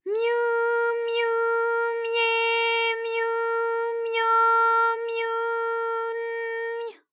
ALYS-DB-001-JPN - First Japanese UTAU vocal library of ALYS.
myu_myu_mye_myu_myo_myu_n_my.wav